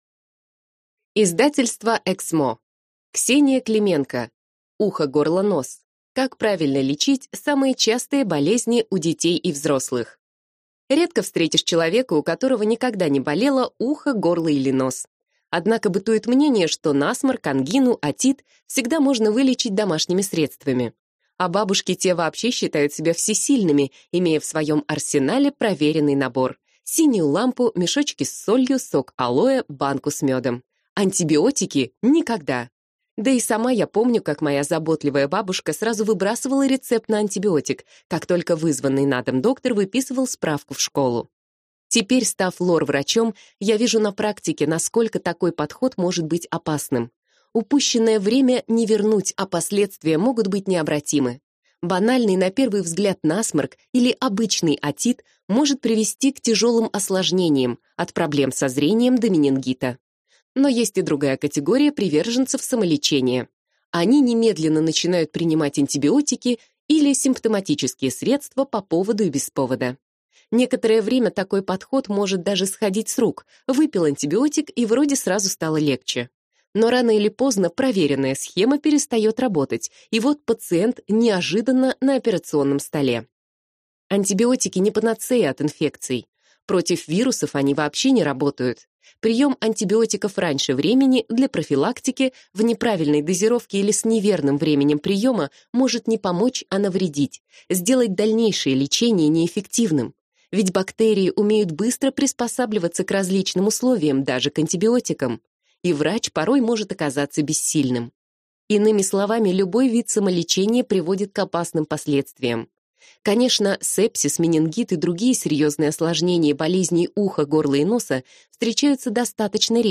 Аудиокнига УХОГОРЛОНОС. Как правильно лечить самые частые болезни у детей и взрослых | Библиотека аудиокниг
Прослушать и бесплатно скачать фрагмент аудиокниги